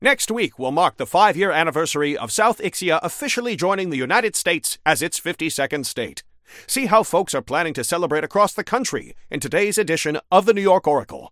Newscaster_headline_63.mp3